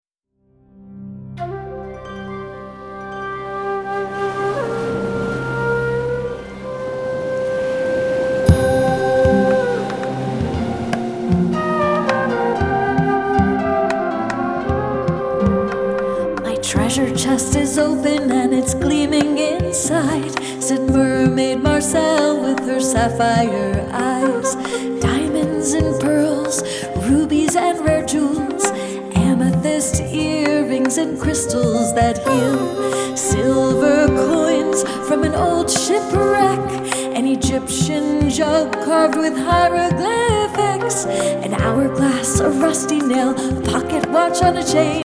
new concept in children's music